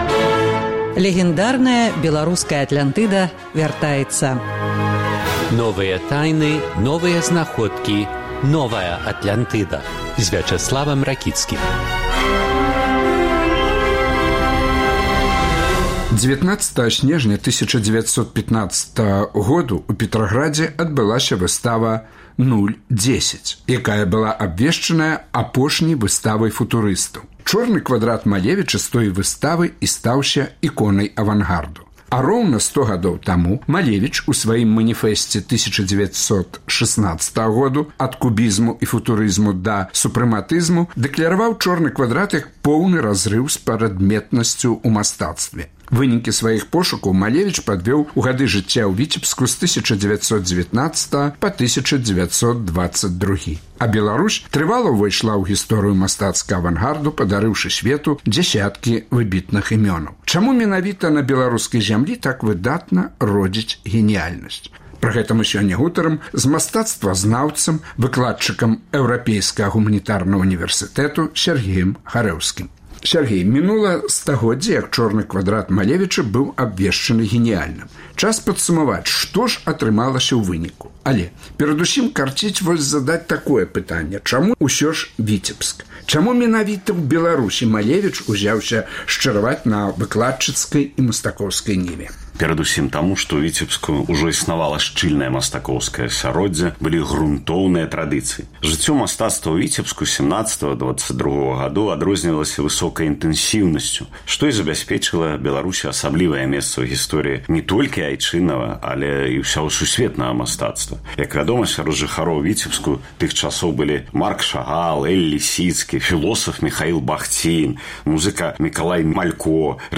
Колькі ўвогуле геніяльных мастакоў Беларусь дала сьвету? Гутарка